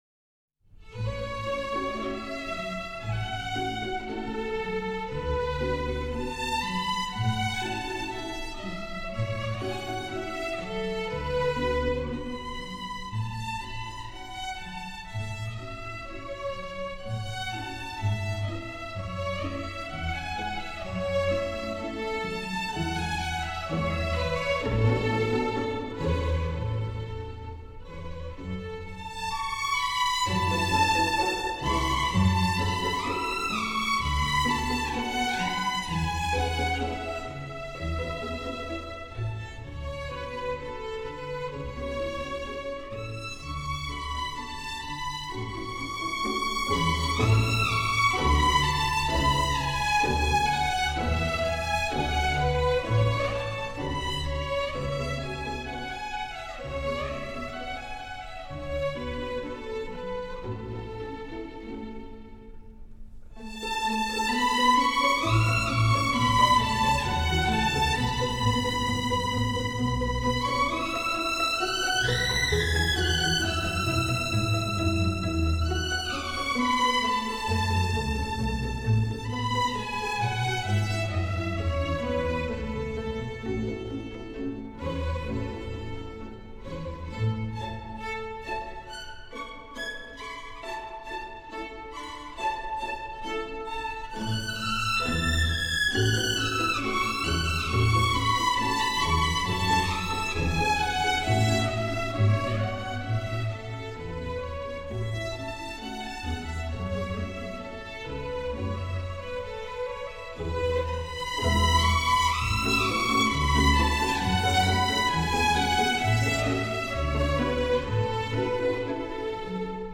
中国管弦乐及小品
含蓄内敛，深沉委婉。
这张精心制作的专辑在录音、数码处理上均为上乘，既体现出管弦乐恢弘的壮丽，也体现出民乐婉转的悠扬。
以第一小提琴为主奏，其他声部（第二小提琴、中提琴、大提琴和低音提琴）以拨弦伴奏